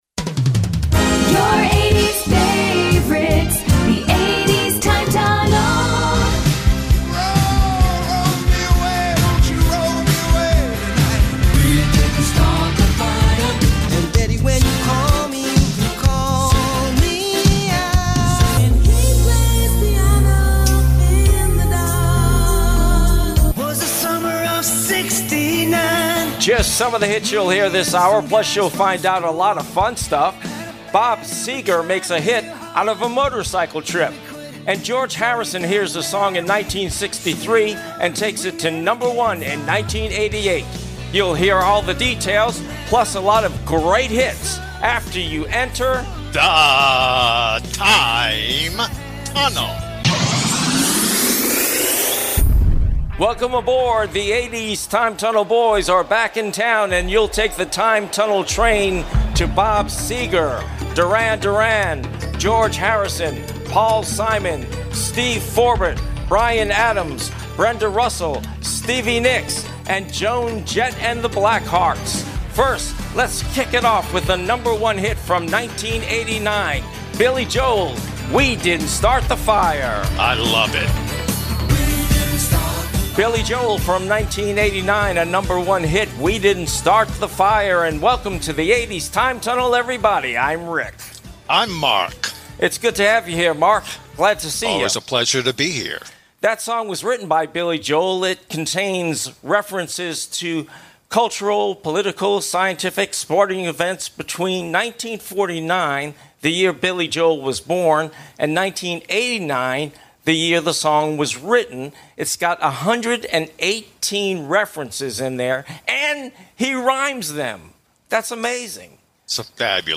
* 80's TIME TUNNEL is a two-hour weekly music intensive 1980s hits show.
* Your listeners will hear the biggest 80s hits as they discover the inspiration behind the tracks.